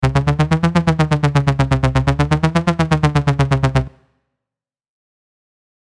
Lead – Charlie
Lead-Charlie.mp3